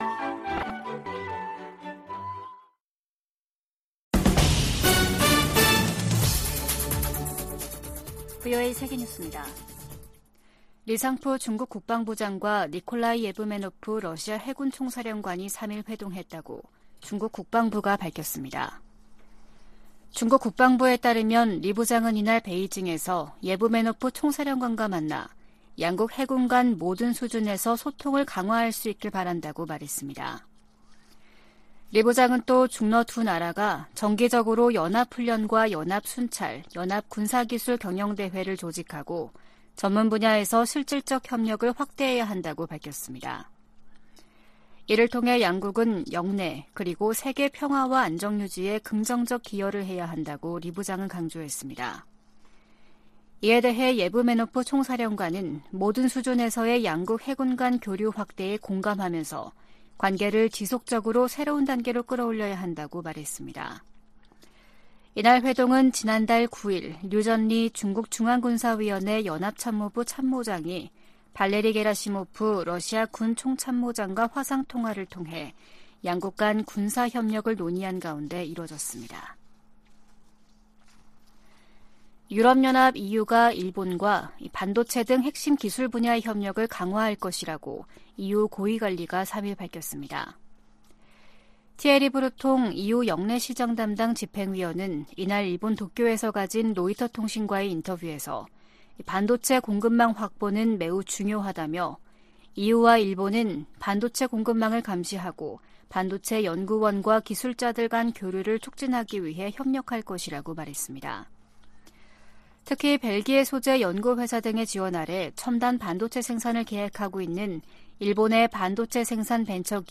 VOA 한국어 아침 뉴스 프로그램 '워싱턴 뉴스 광장' 2023년 7월 4일 방송입니다. 최근 미국 의회에서는 본토와 역내 미사일 방어망을 강화하려는 움직임이 나타나고 있습니다.